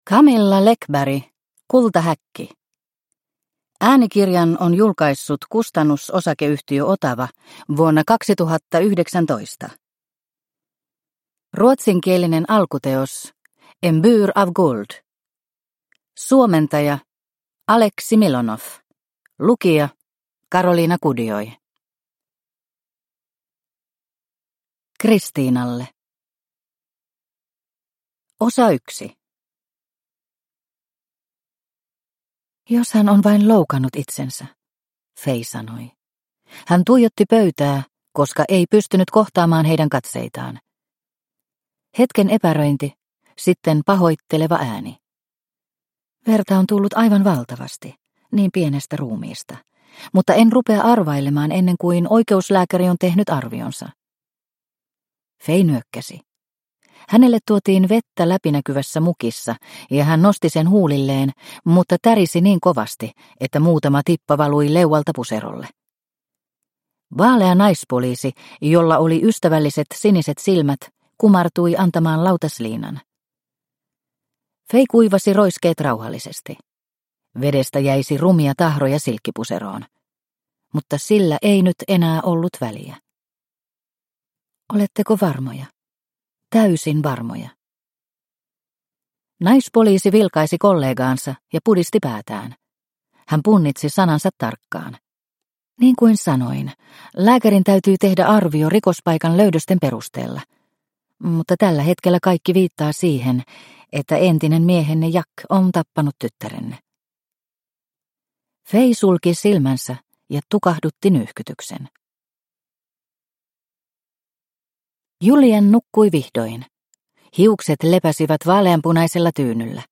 Kultahäkki – Ljudbok – Laddas ner